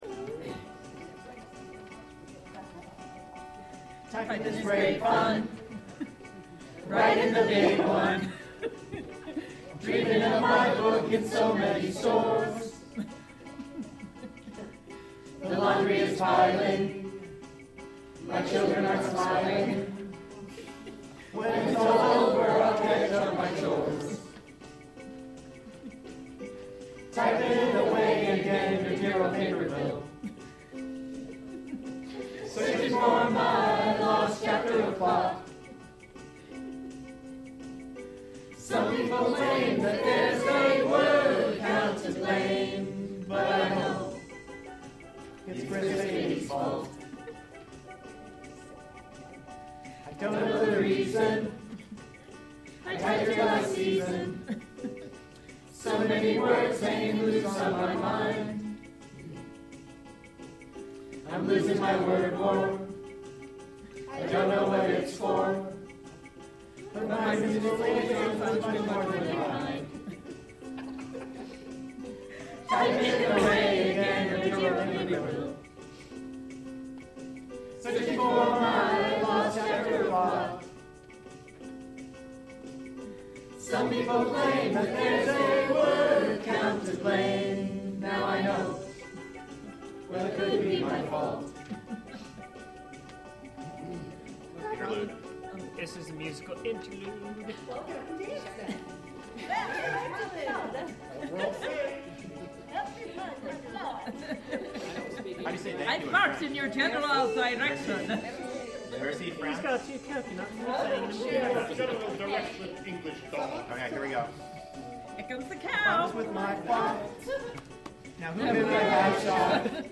We also sang songs and listened to the songs and videos from France that they gave us to pay off the bet from our word war.
Here is the song from our TGIO.